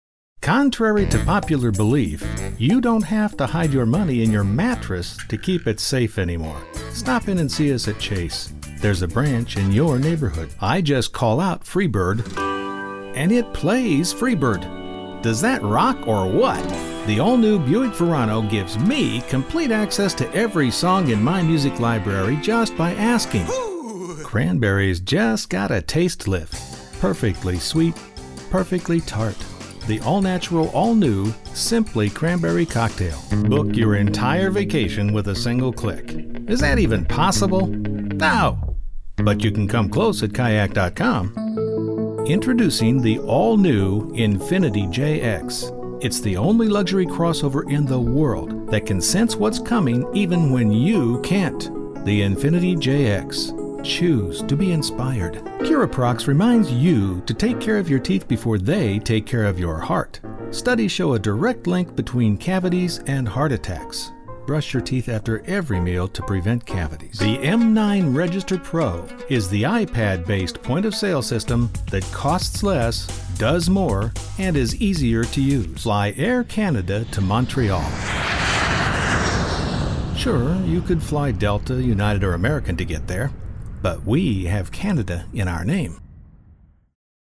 English middle aged guy next door warm believable smooth voice.
middle west
Sprechprobe: Sonstiges (Muttersprache):